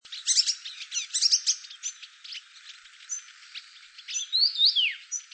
19-3阿里山冠羽groupcal1.mp3
物種名稱 冠羽畫眉 Yuhina brunneiceps
錄音地點 嘉義縣 阿里山 阿里山
錄音環境 森林
行為描述 群體叫
標籤/關鍵字 鳥